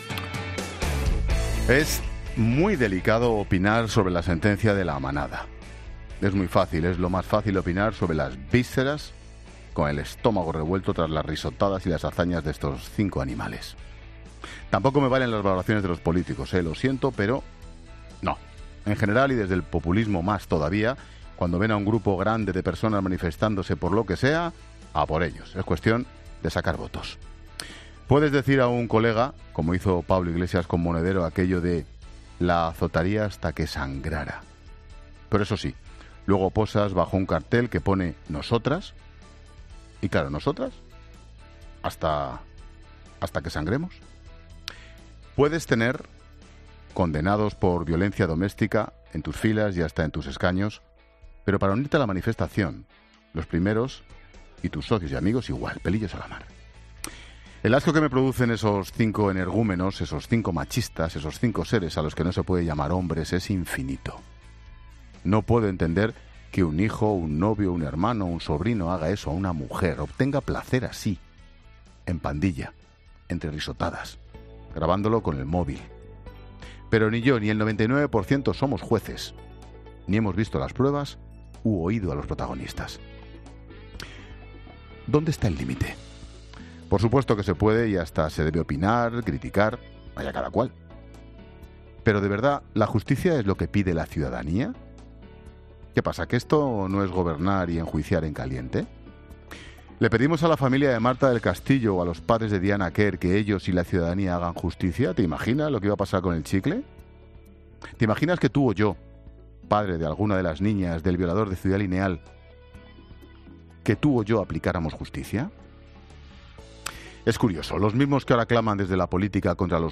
Monólogo de Expósito
El comentario de Ángel Expósito sobre la sentecia a La Manada.